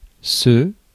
Ääntäminen
Ääntäminen France: IPA: /sə/ Tuntematon aksentti: IPA: /kɑz/ Lyhenteet ja supistumat c' Haettu sana löytyi näillä lähdekielillä: ranska Käännös Ääninäyte Pronominit 1. it UK US 2. this US 3. that US Suku: m .